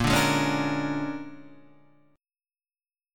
A#7#9 chord {6 5 6 6 3 6} chord